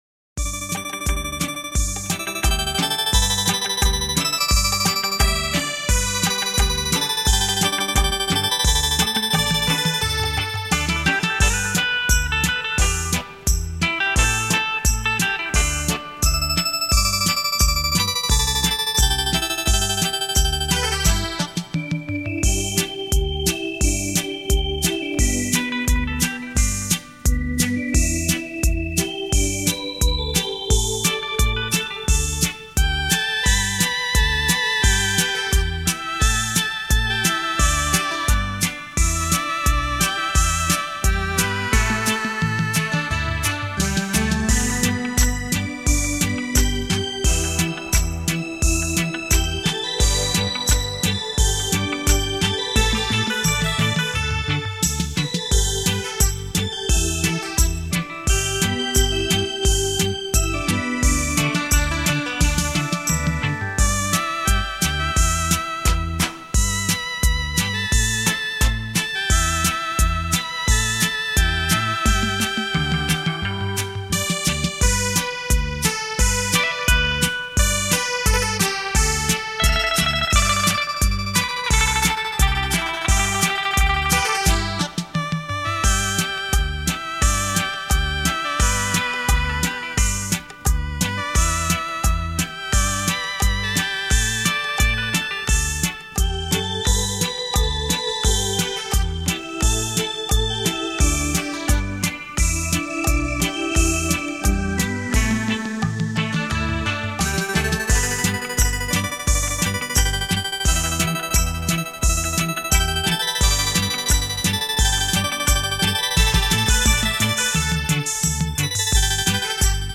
电子琴诠释经典 表现出另一种风情
经典的歌曲 全新的演绎 浪漫双电子琴带你回味往事